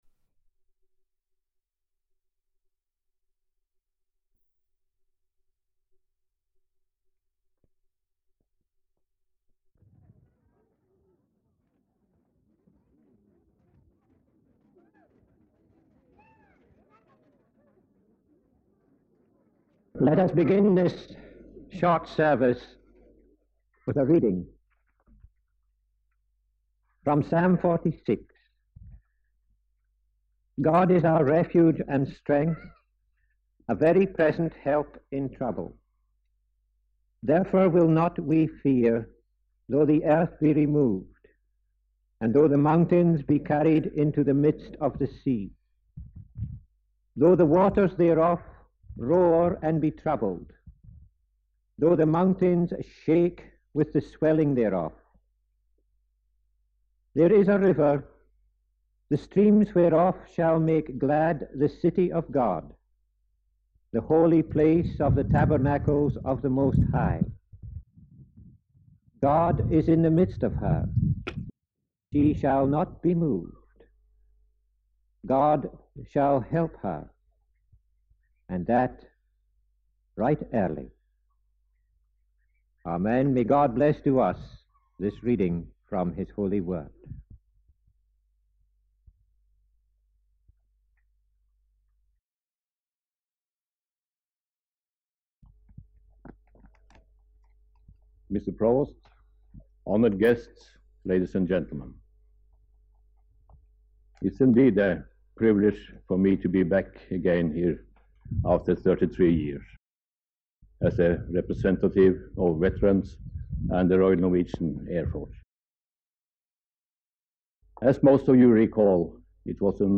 Woodhaven-Speeches-4th-May-1975.mp3